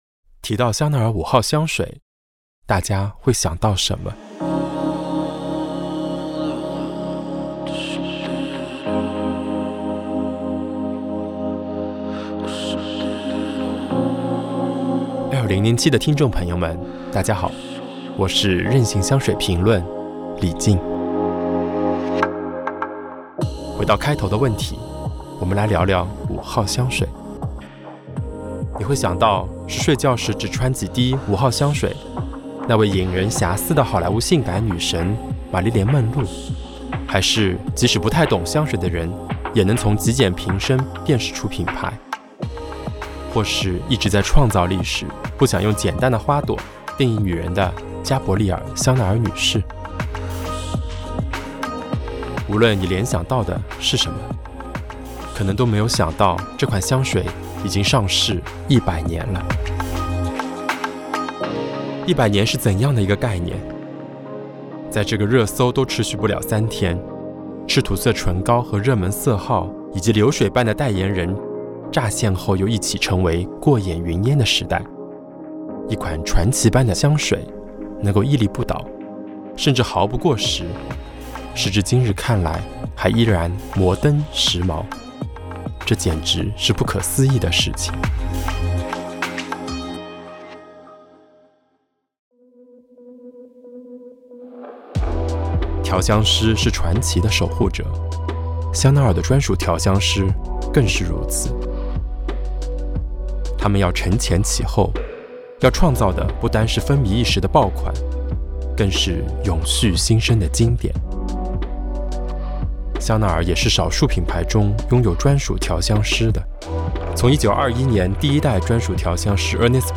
采访嘉宾